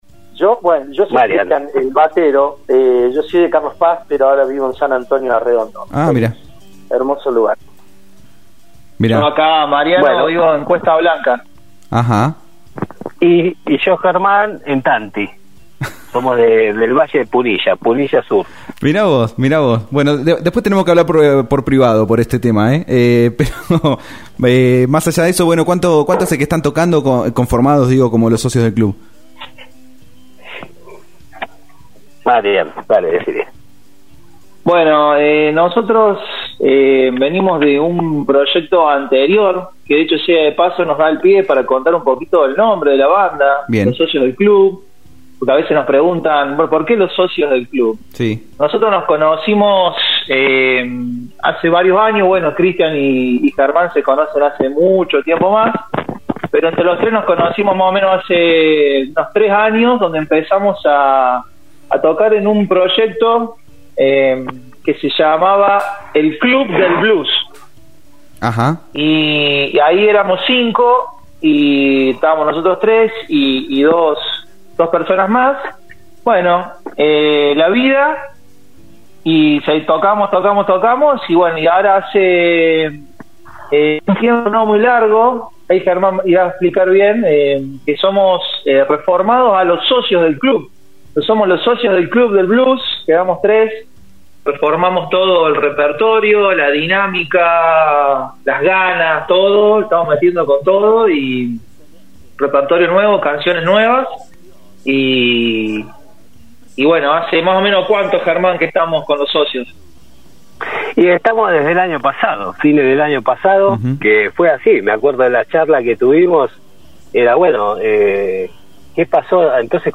Notas y Entrevistas realizadas en Om Radio